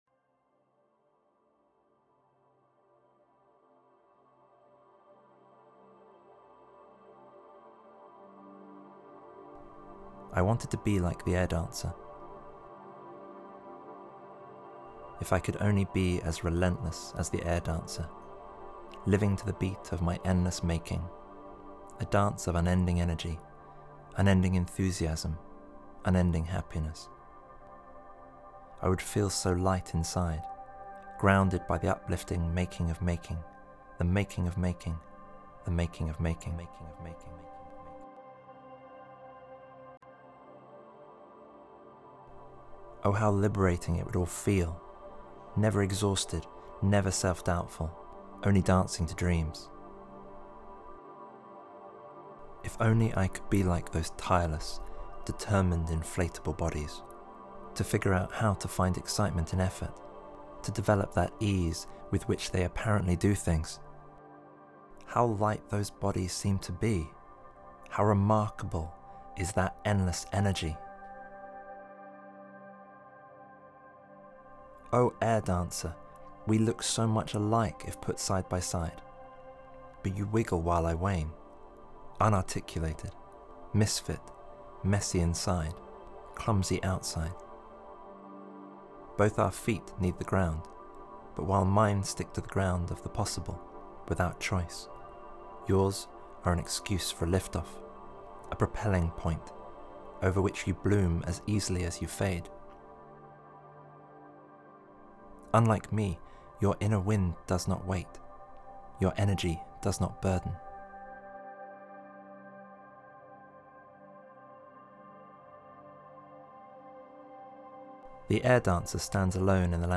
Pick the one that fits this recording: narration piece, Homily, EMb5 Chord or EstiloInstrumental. narration piece